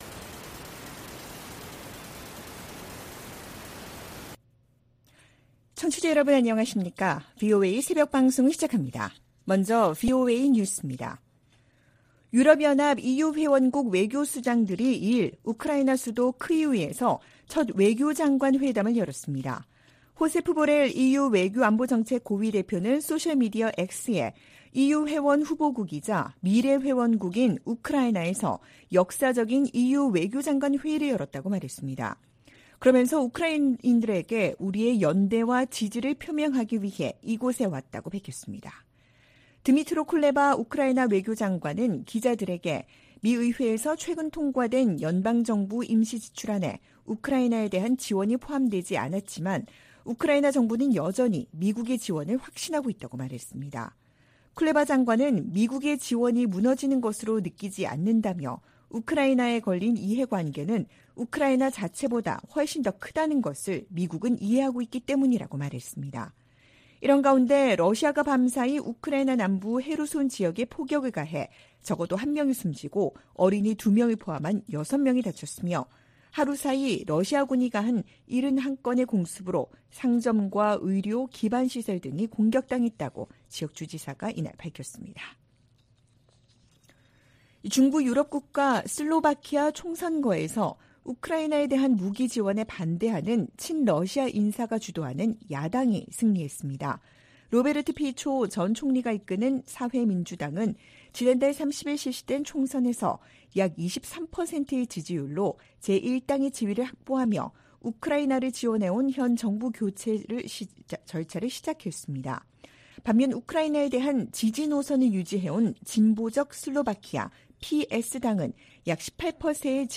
VOA 한국어 '출발 뉴스 쇼', 2023년 10월 3일 방송입니다. 국제원자력기구(IAEA)는 오스트리아에서 열린 제67차 정기총회에서 북한의 지속적인 핵 개발을 규탄하고, 완전한 핵 폐기를 촉구하는 결의안을 채택했습니다. 북한이 핵 보유국 지위를 부정하는 국제사회 비난 담화를 잇달아 내놓고 있습니다.